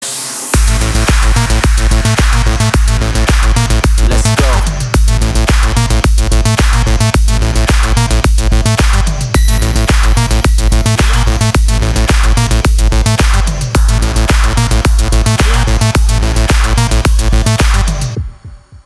• Качество: 320, Stereo
ритмичные
громкие
Стиль: electro house